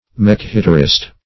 Mekhitarist \Mekh"i*tar*ist\, n.